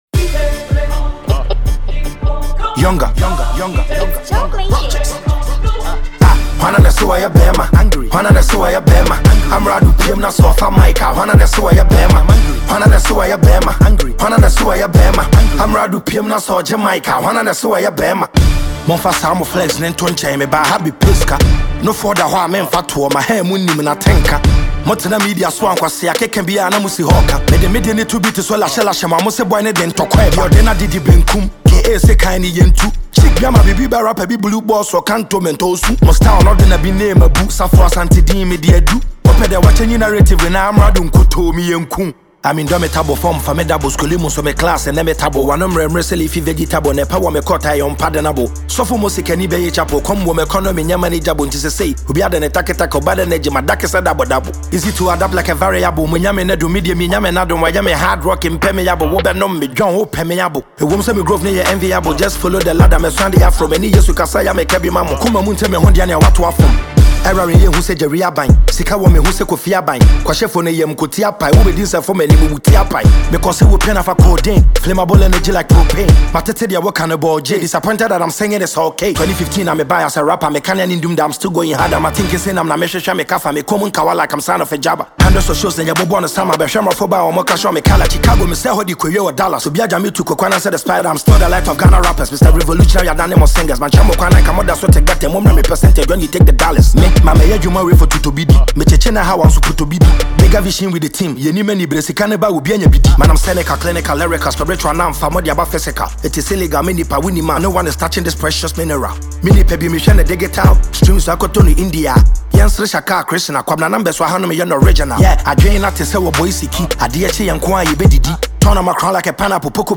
a rap track